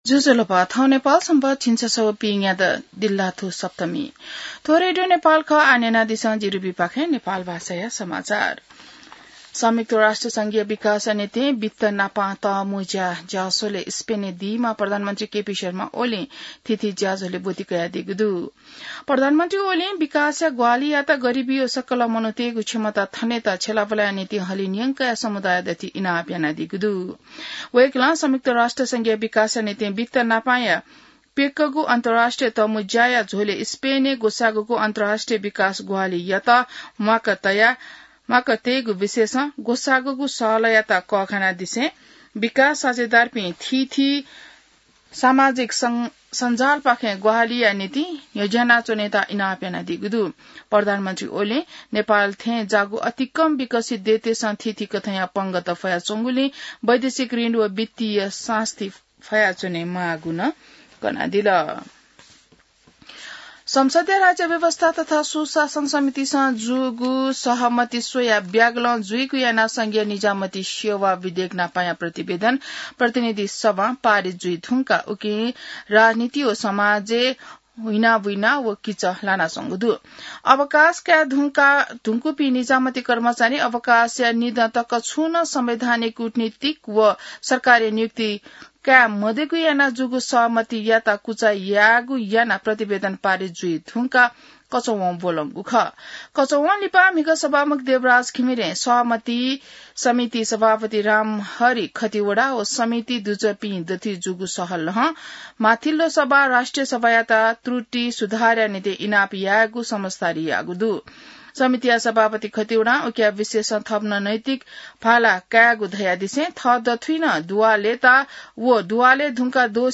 नेपाल भाषामा समाचार : १८ असार , २०८२